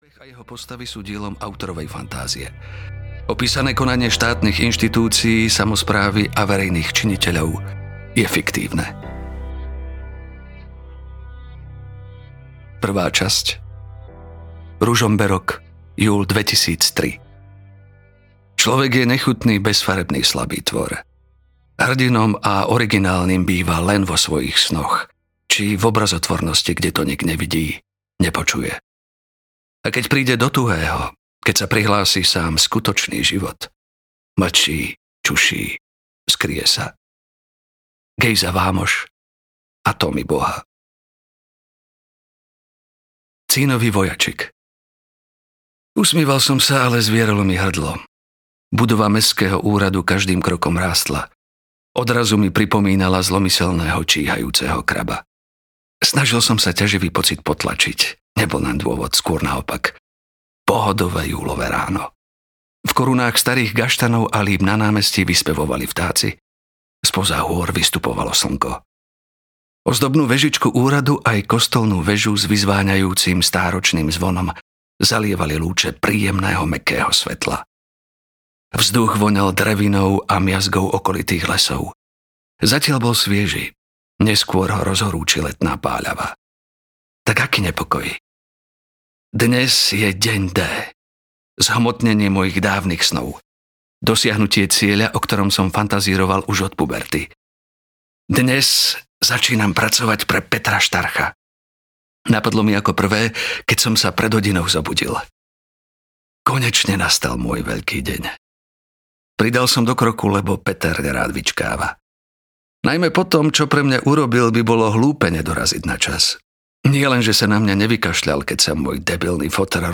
Čierny kruh: Koniec mafie audiokniha
Ukázka z knihy